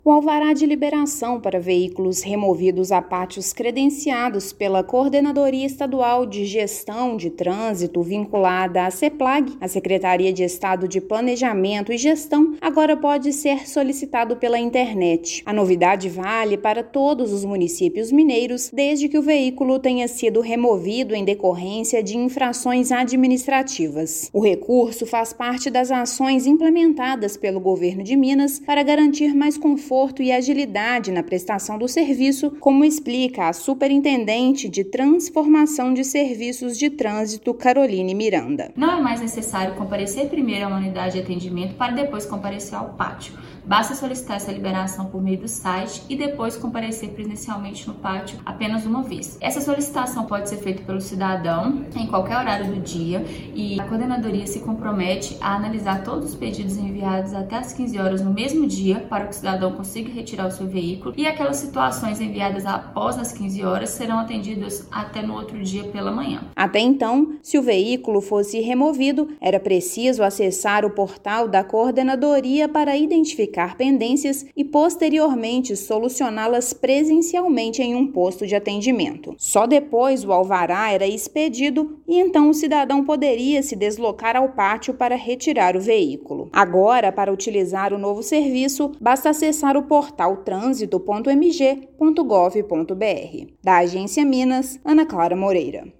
Serviço está disponível para automóveis transportados a pátios credenciados pela Coordenadoria Estadual de Gestão de Trânsito. Ouça matéria de rádio.